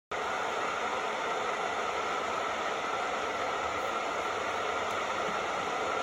Écouter des sons modifiés
Trois sons différents ont été enregistrés puis modifiés de deux façons.